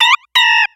Cri de Pandespiègle dans Pokémon X et Y.